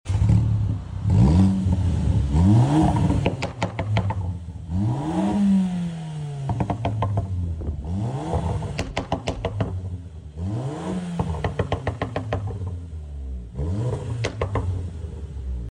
2014 VW Golf GTI came sound effects free download
2014 VW Golf GTI came in for a back box delete with a stage 1 remap and tcu tune.